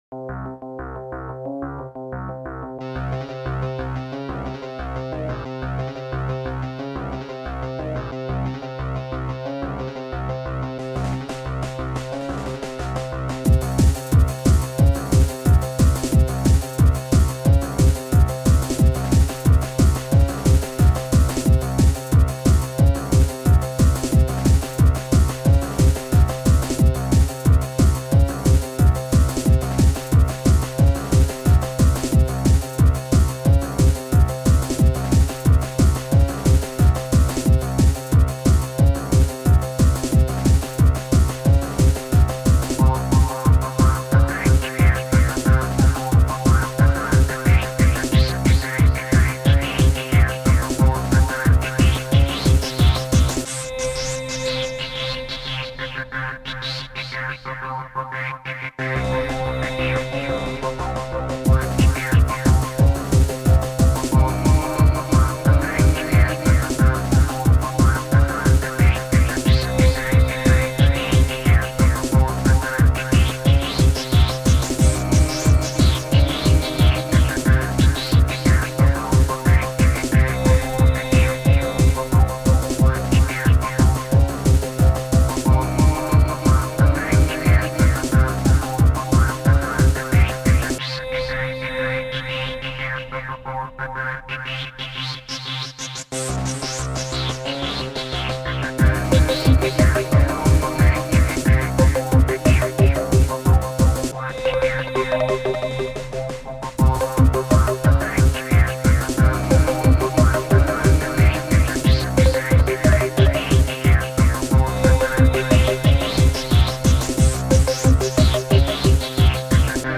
Genre= Trance